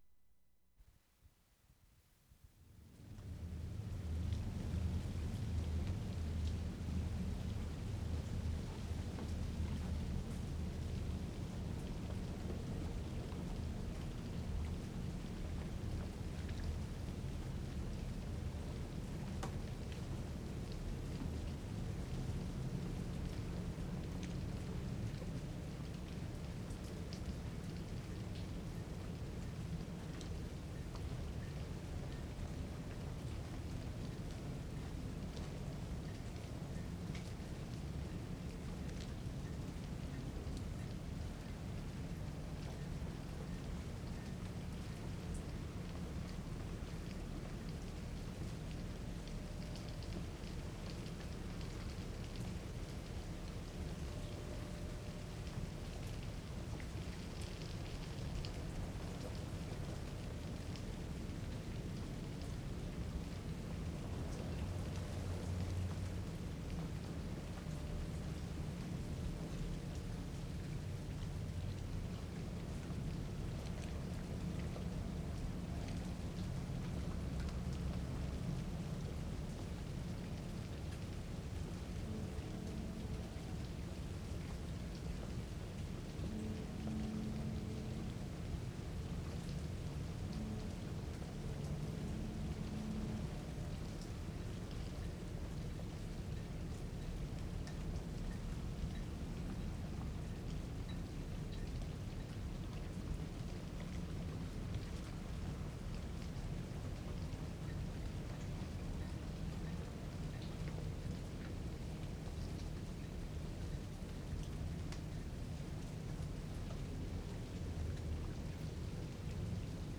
WORLD SOUNDSCAPE PROJECT TAPE LIBRARY
BOAT HORN, Port Moody 6'05"
1. Ambience by waterfront with rain,The horn is distant, growling, very low.
1'25" horn, strange, deep pitched, very distant.
2'45" again, lower level still.
5'40" train whistle.